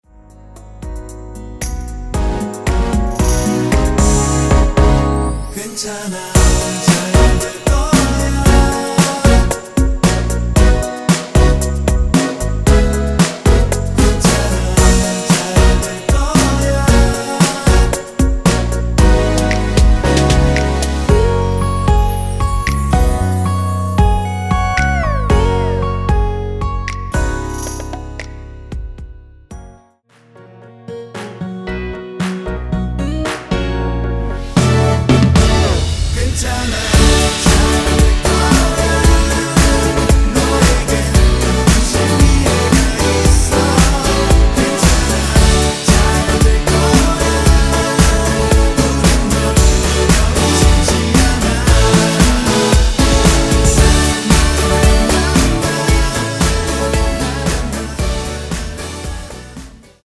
원키에서(-1)내린 코러스 포함된 MR입니다.(미리듣기 확인)
F#
앞부분30초, 뒷부분30초씩 편집해서 올려 드리고 있습니다.
중간에 음이 끈어지고 다시 나오는 이유는